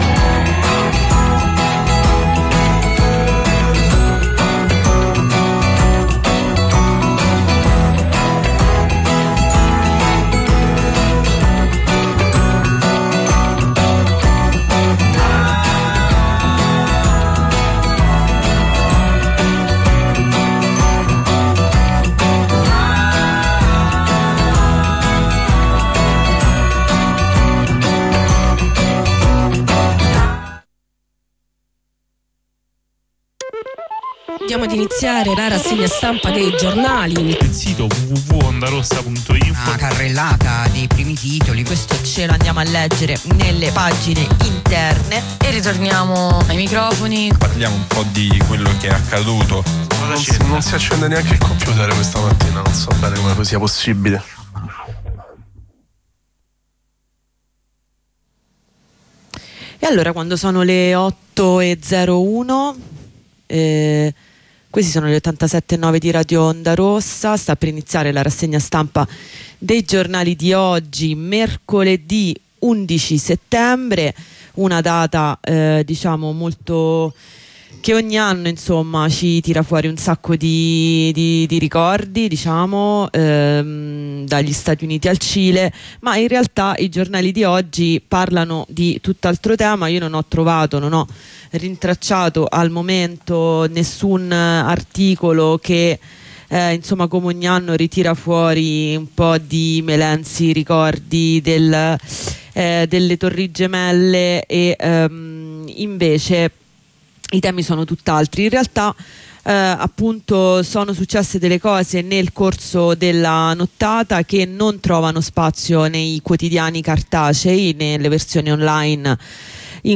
Rassegna stampa
Lettura dei principali quotidiani nazionali e locali di Mercoledì 11\09\2024